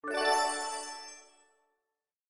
artifact_down.mp3